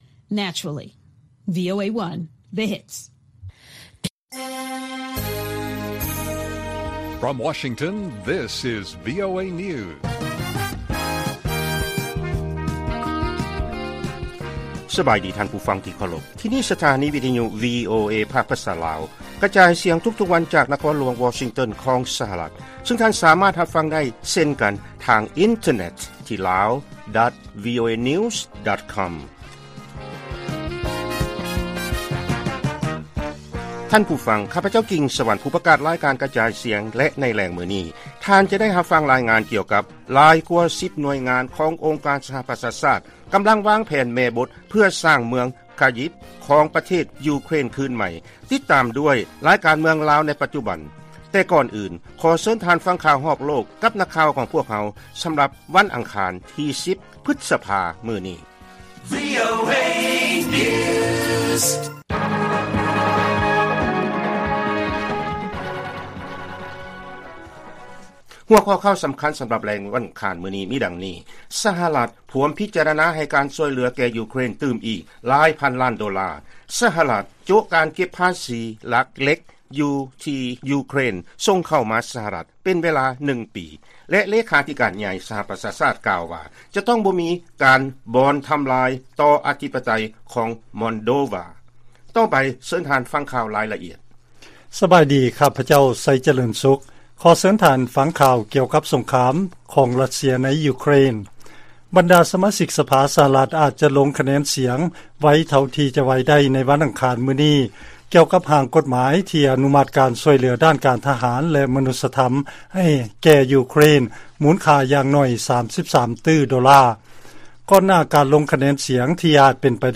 ວີໂອເອພາກພາສາລາວ ກະຈາຍສຽງທຸກໆວັນ, ຫົວຂໍ້ຂ່າວສໍາຄັນໃນມື້ນີ້ມີ: 1. ສຫລ ພວມພິຈາລະນາໃຫ້ການຊ່ວຍເຫຼືອແກ່ ຢູເຄຣນ, 2. ສຫລ ໂຈະການເກັບພາສີເຫຼັກທີ່ ຢູເຄຣນ ສົ່ງເຂົ້າມາ ສຫລ ເປັນເວລານຶ່ງປີ, ແລະ 3. ເລຂາທິການໃຫຍ່ ສປຊ ກ່າວວ່າ ຈະຕ້ອງບໍ່ມີການບ່ອນທຳລາຍຕໍ່ອະທິປະໄຕ ມອນໂດວາ.